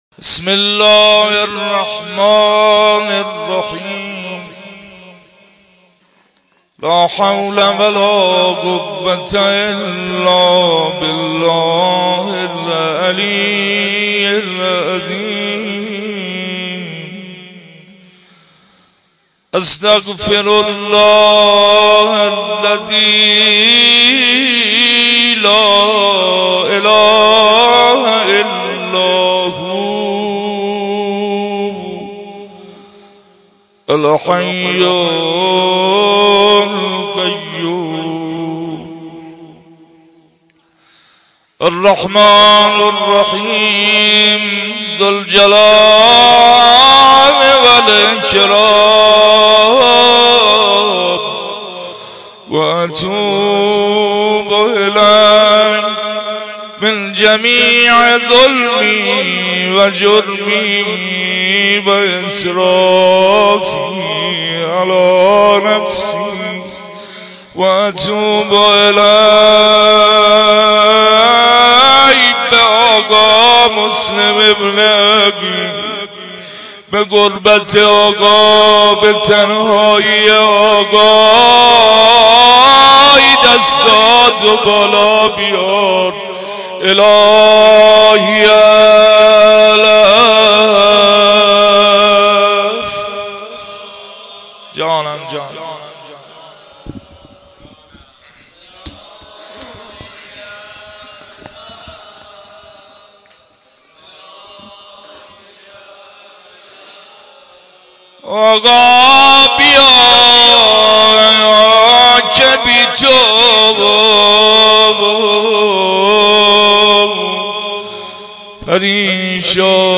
جلسه هفتگی96/6/8هیئت الزهرا(س)کرمان